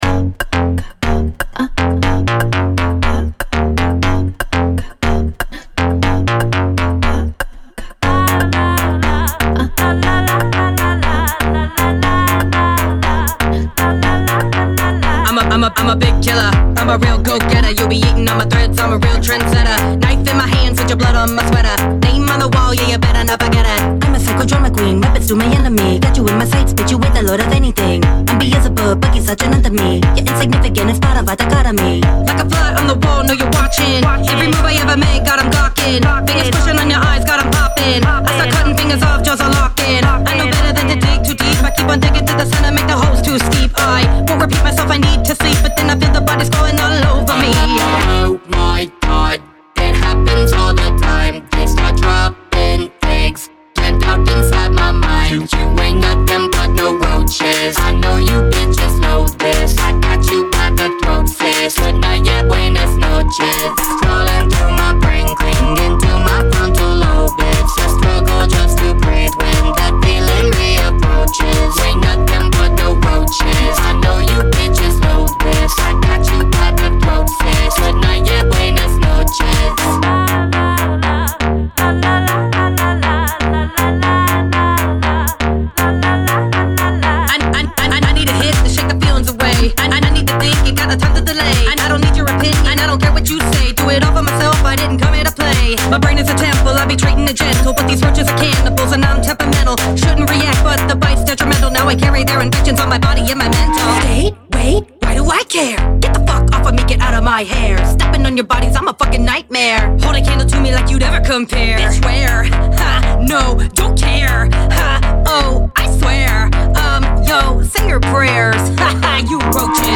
BPM120-120
Audio QualityPerfect (High Quality)
Alternative Pop Song for StepMania, ITGmania, Project Outfox
Full length song (not arcade length cut)